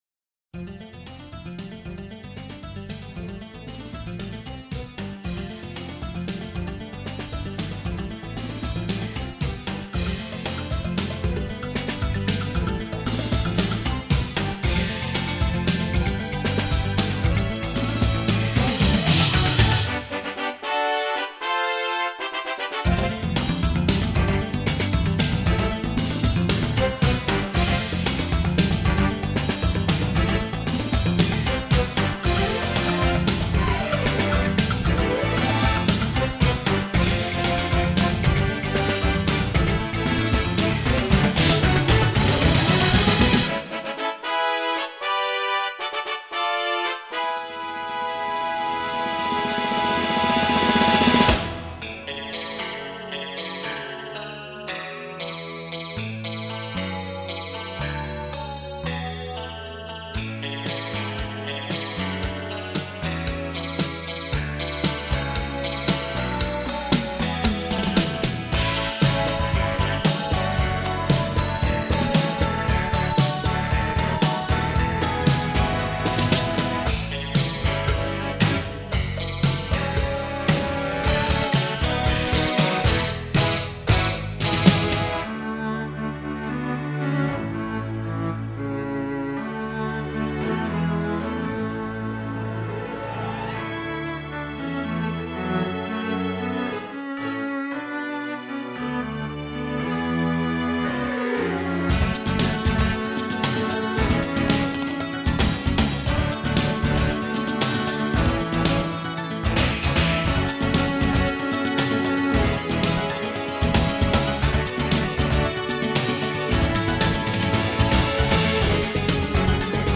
The main 12-string acoustic guitar riff is in 18/8 (5+5+5+3)
Includes some nice string glissando samples.